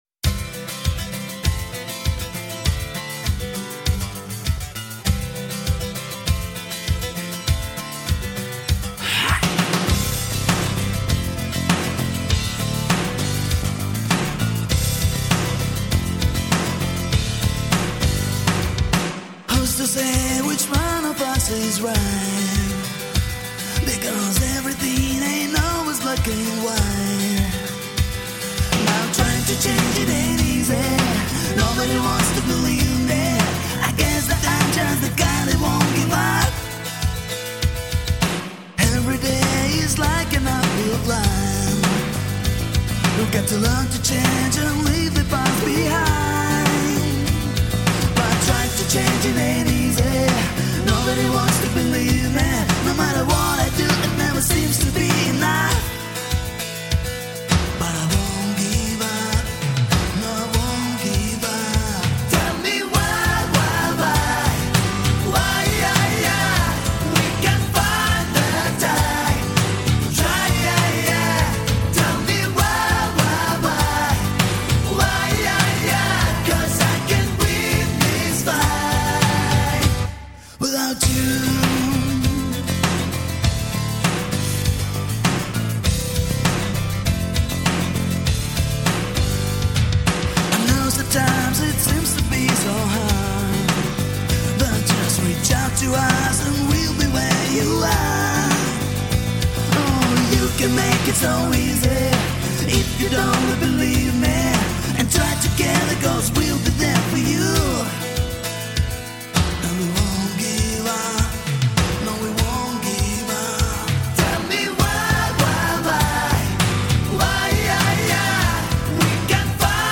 Жанр: hardrock